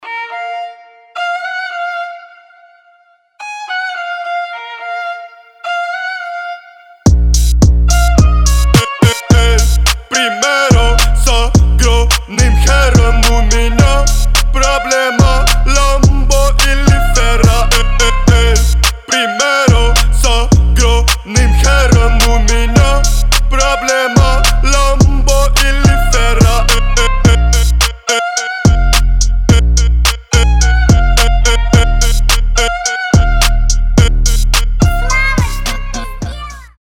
мощные басы
дуэт
качающие
виолончель
грубые